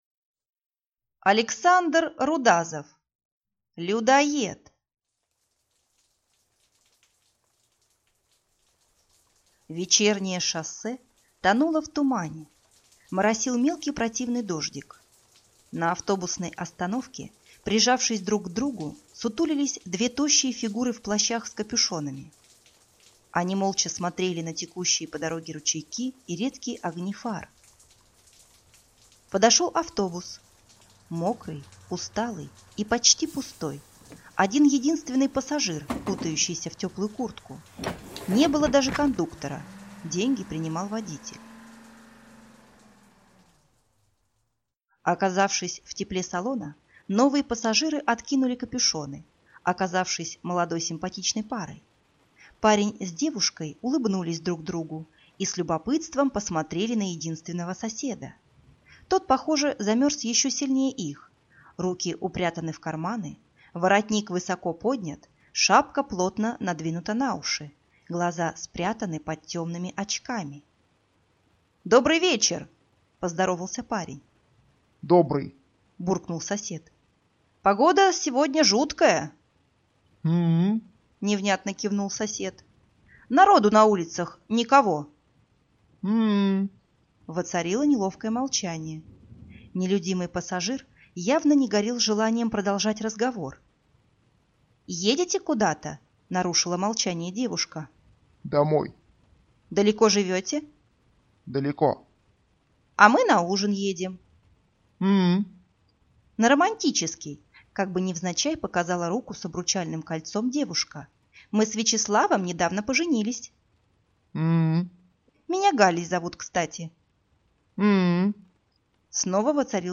Аудиокнига Людоедоед | Библиотека аудиокниг